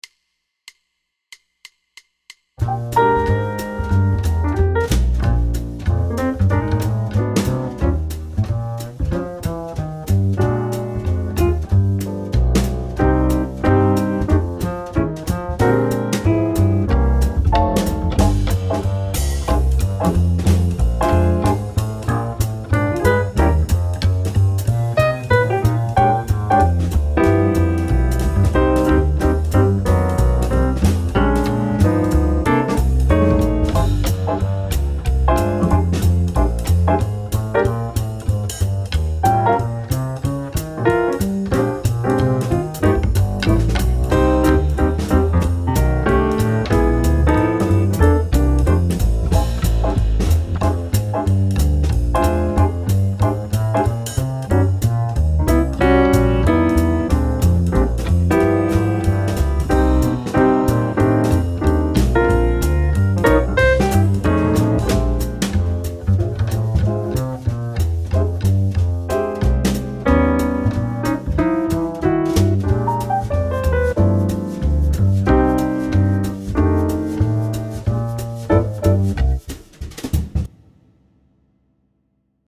The Mp3 track is set up with 5 choruses in mind.